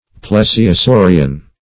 \Ple`si*o*sau"ri*an\
plesiosaurian.mp3